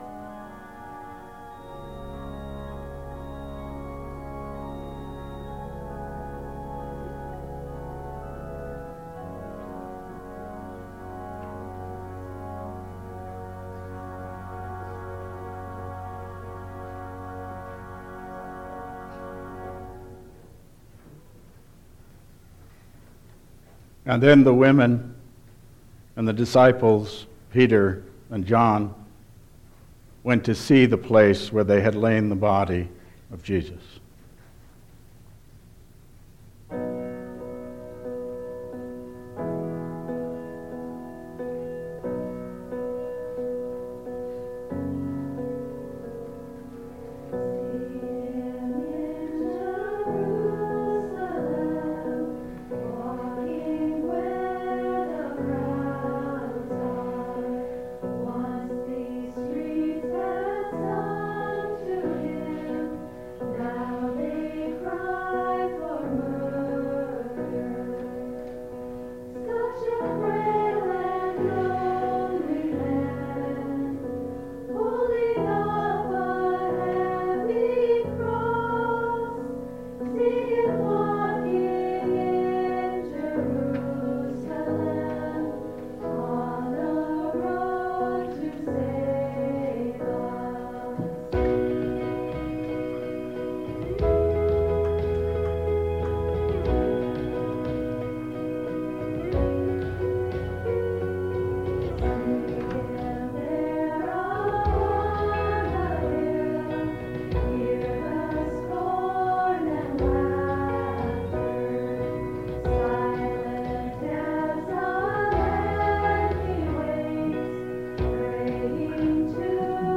Download Files Printed Sermon and Bulletin